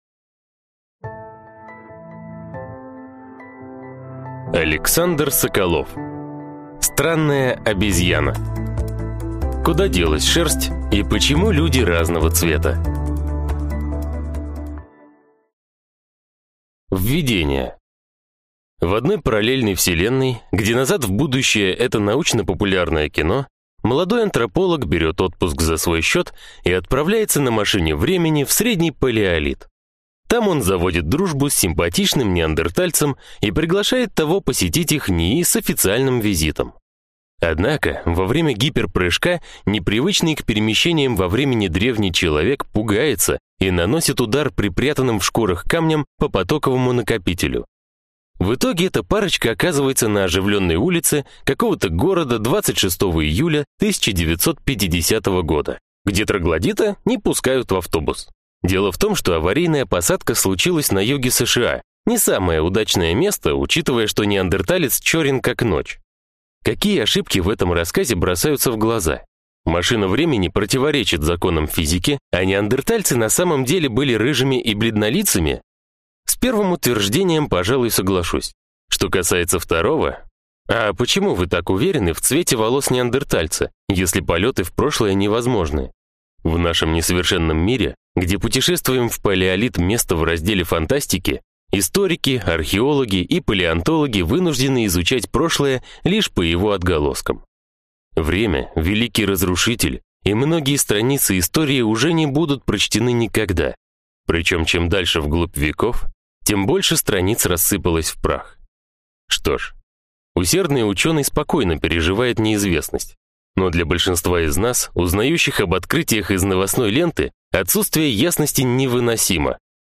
Аудиокнига Странная обезьяна. Куда делась шерсть и почему люди разного цвета | Библиотека аудиокниг